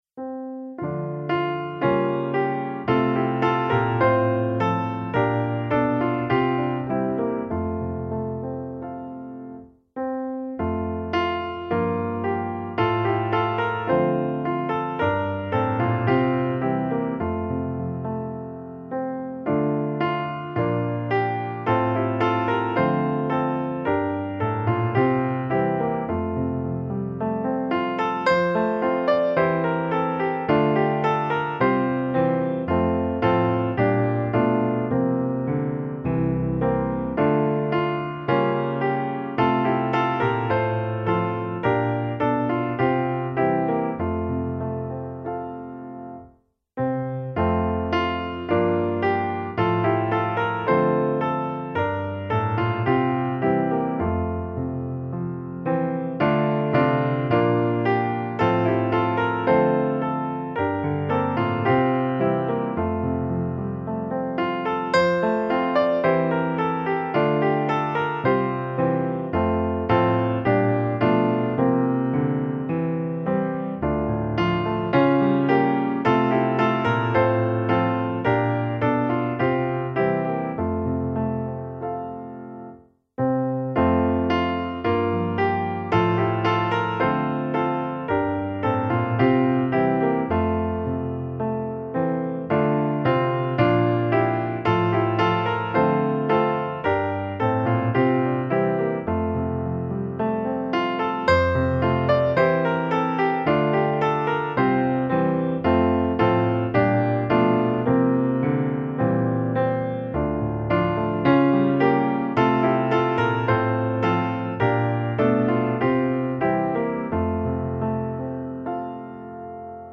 O Betlehem, du lilla stad - musikbakgrund
Musikbakgrund Psalm